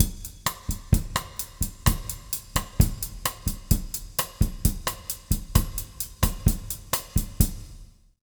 130BOSSA05-R.wav